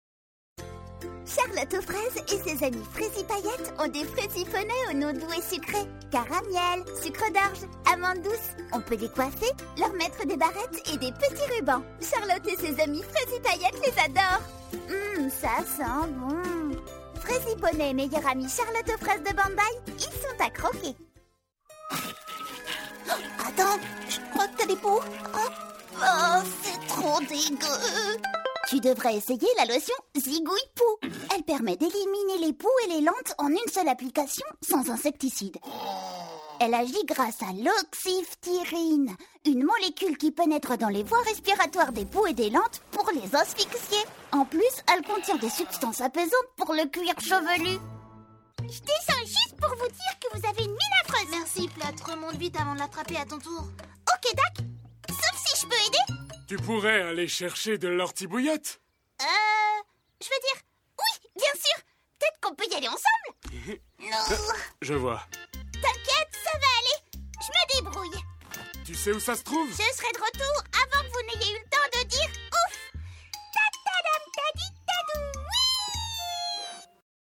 Animation
Mezzo-Soprano